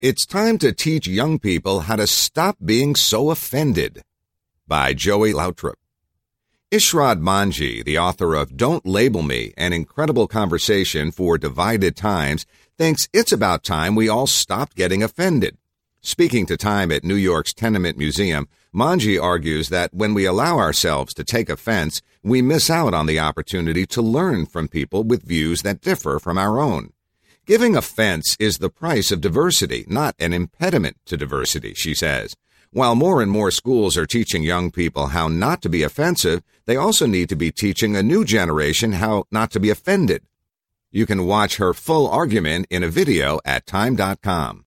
Irshad Manji, author of Don’t Label Me: An Incredible Conversation for Divided Times, thinks it’s about time we all stopped getting offended. Speaking to TIME at New York’s Tenement Museum, Manji argues that when we allow ourselves to take offense, we miss out on the opportunity to learn from people with views that differ from our own.